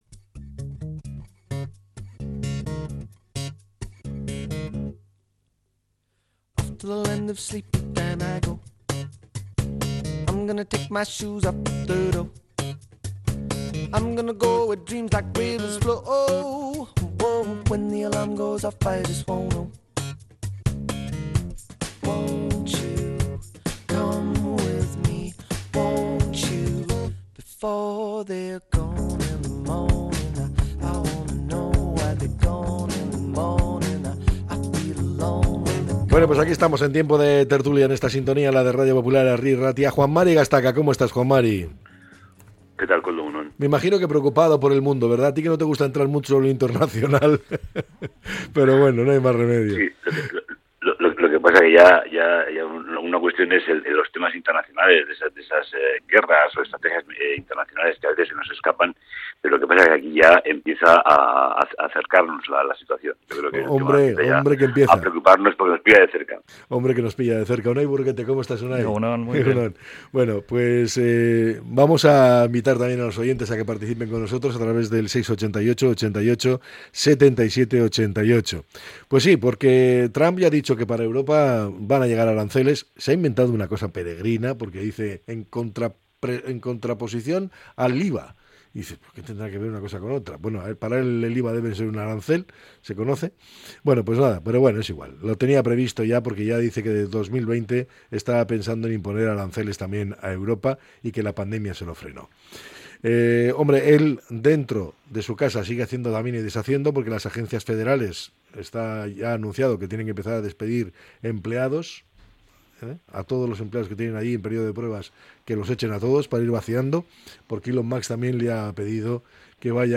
La tertulia 14-02-25.